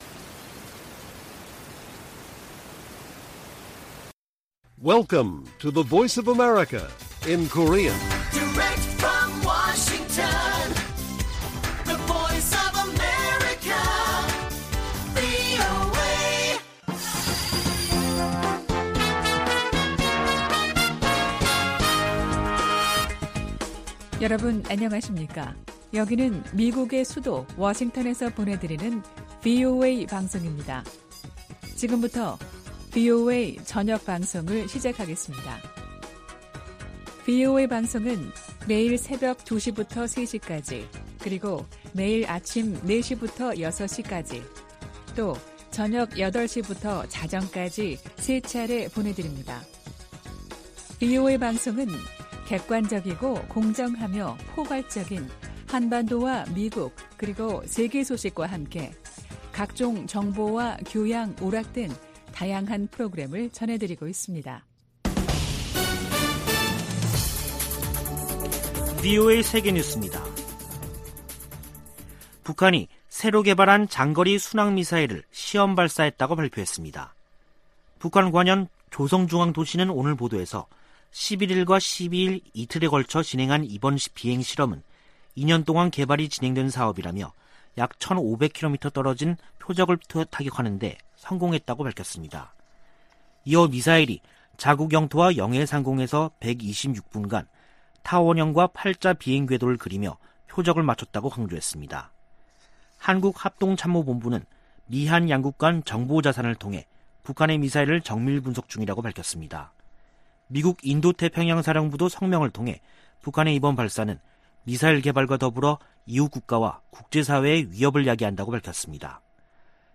VOA 한국어 간판 뉴스 프로그램 '뉴스 투데이' 2021년 9월 13일 1부 방송입니다. 북한이 미-한 연합훈련 반발 담화를 낸 지 한 달 만에 신형 장거리 순항미사일을 시험발사했다고 밝혔습니다.